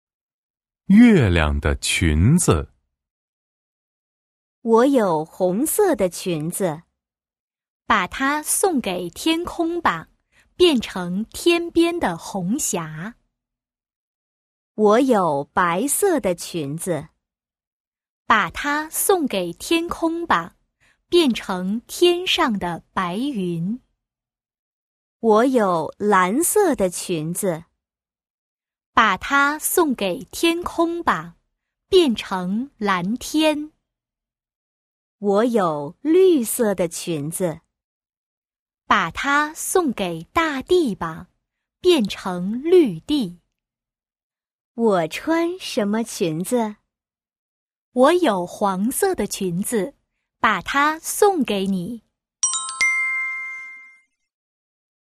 5. Đọc chuyện